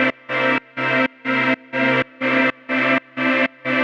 Index of /musicradar/sidechained-samples/125bpm